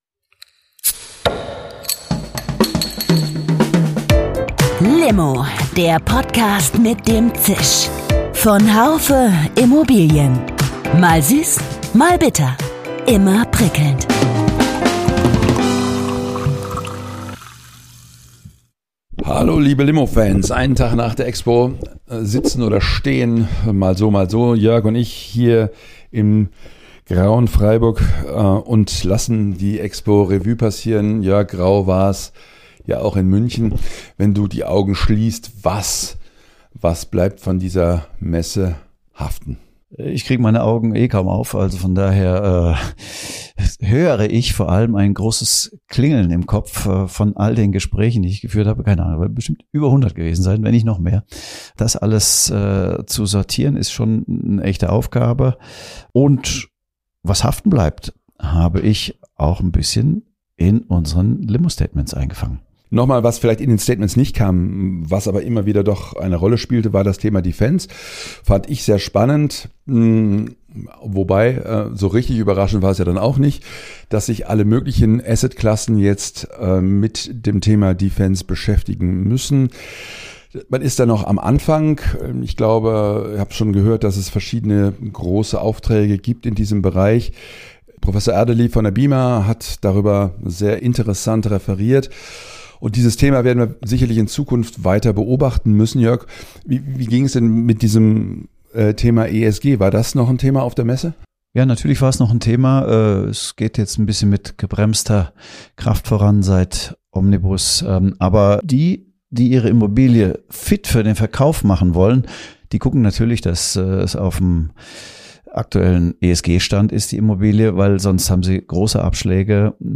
Die Expo Real war mit rund 42.000 Teilnehmern leicht besser besucht als im Vorjahr. Wohnen war ein großer Schwerpunkt der Messe.